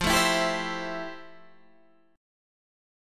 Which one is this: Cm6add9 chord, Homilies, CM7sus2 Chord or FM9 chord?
FM9 chord